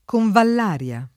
[ konvall # r L a ]